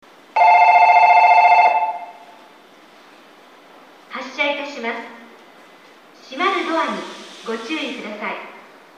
駅放送
発車 ピッチが少し高い --